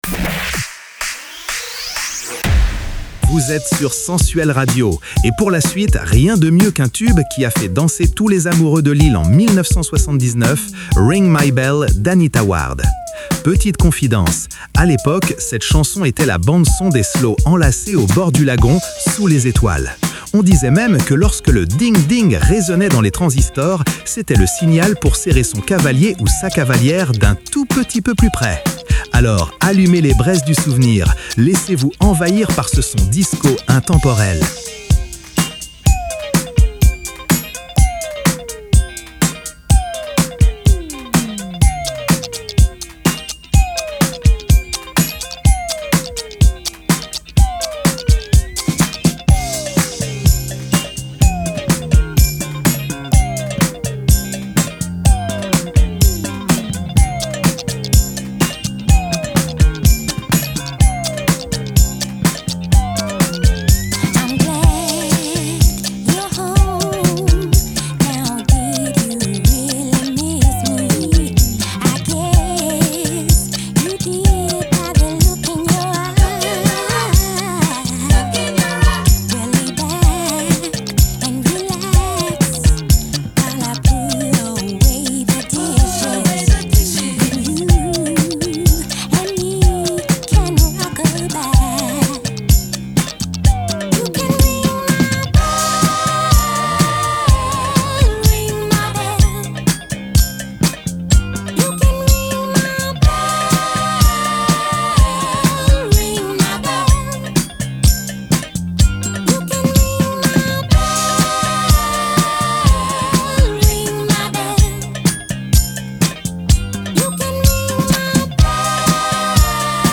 La voix douce et sensuelle